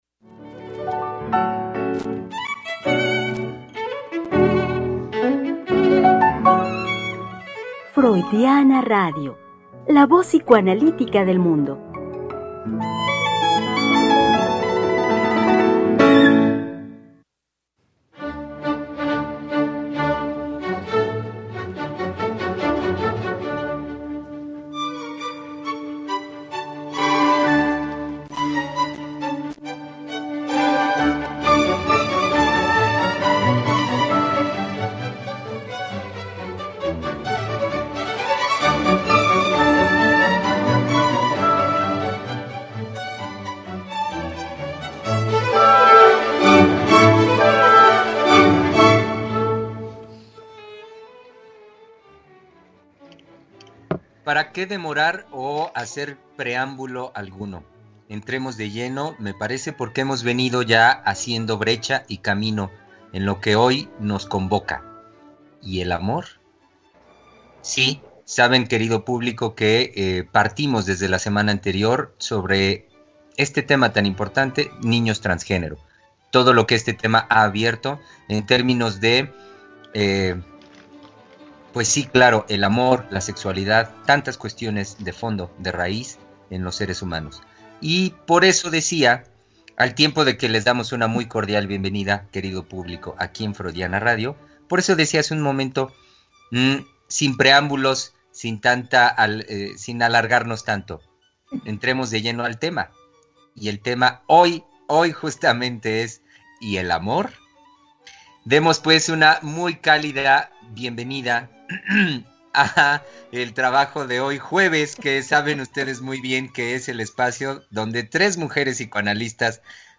Tres Mujeres Psicoanalistas Hablando de la Vida Cotidiana.
Conversación